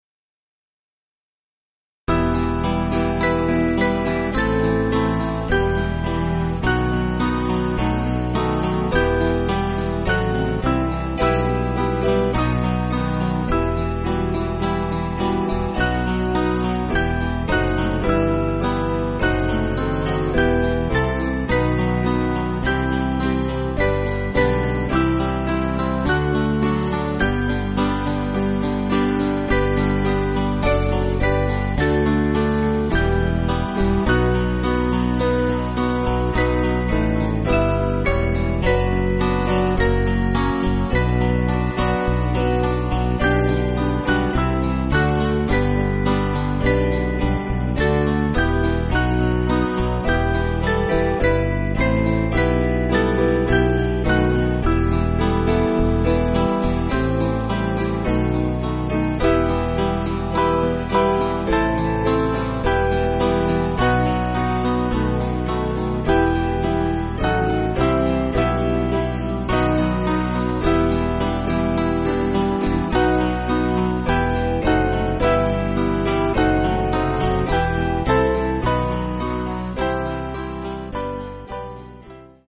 Small Band
(CM)   4/Em